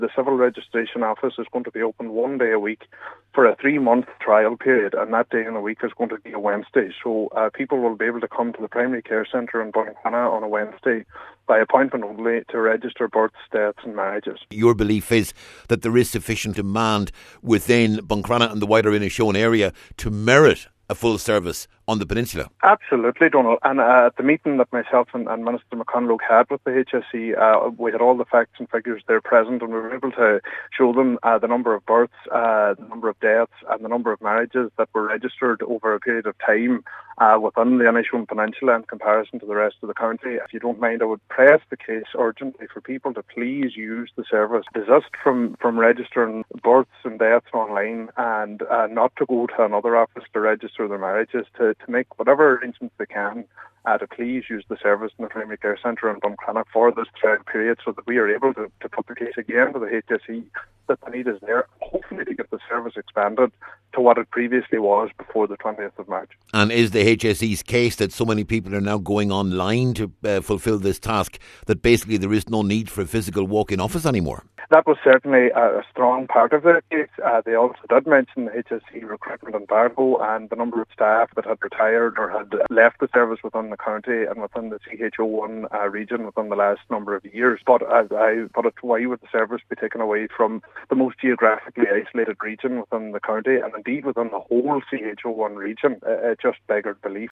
Cllr Bradley is urging people to use the service once it opens…………….